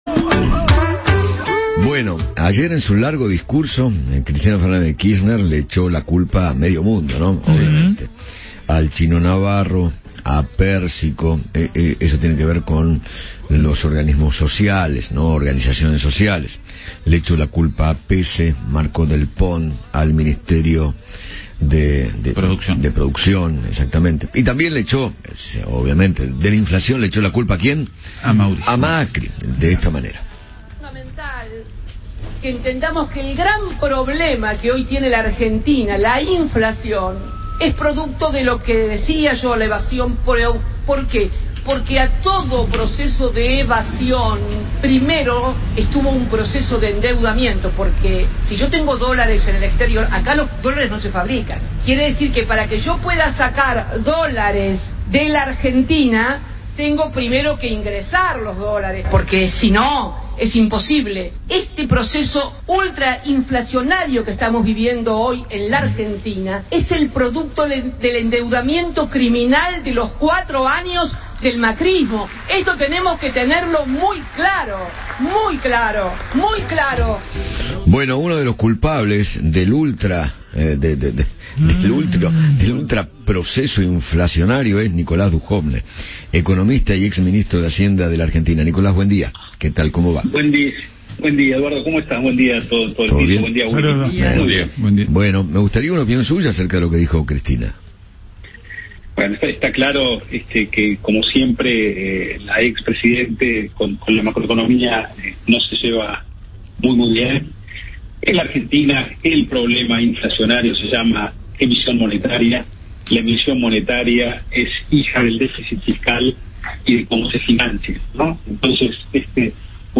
Eduardo Feinmann dialogó con Nicolás Dujovne, ex Ministro de Hacienda de la Nación, sobre las declaraciones de Cristina Fernandez, quien le echó la culpa de la inflación al Gobierno de Macri, y defendió lo realizado en su gestión.